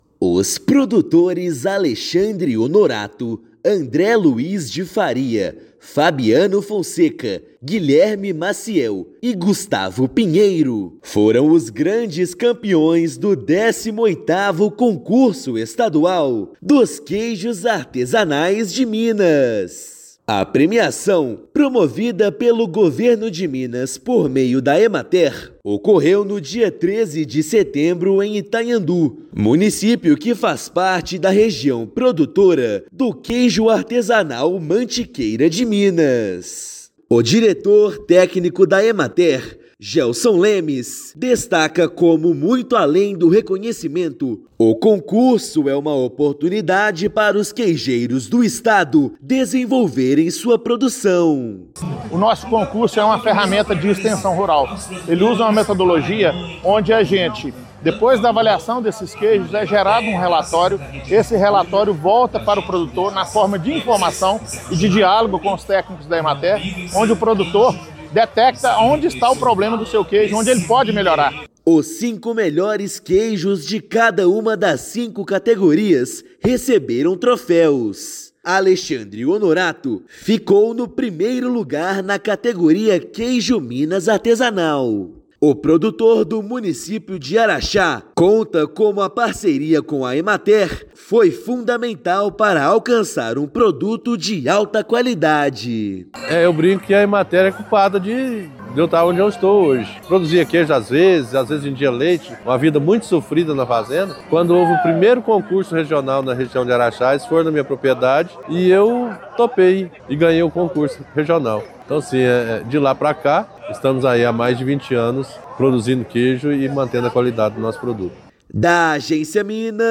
A 18ª edição, realizada no município de Itanhandu, premiou produtores em cinco categorias. Ouça matéria de rádio.